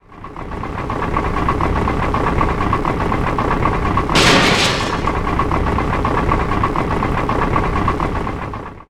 dronein.ogg